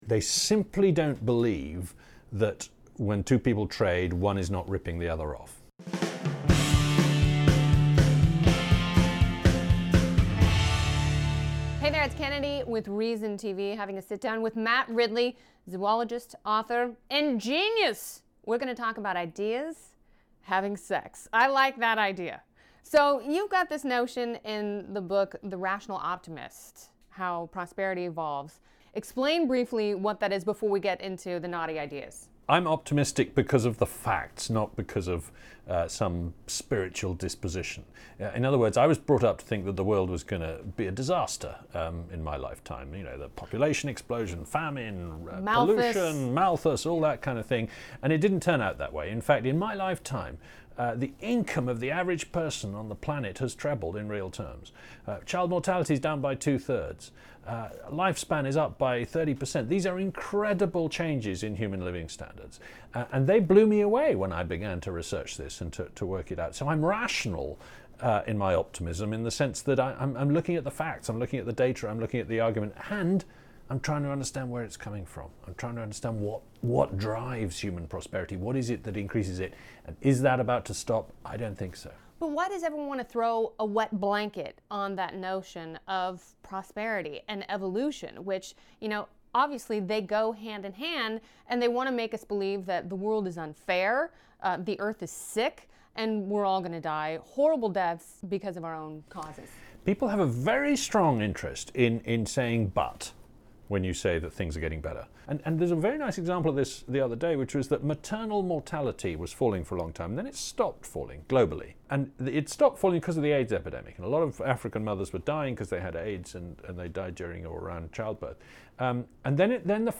Ridley sat down with Reason's Kennedy to discuss his thoughts on free trade, ideas having sex and the irrationality of apocalyptic science.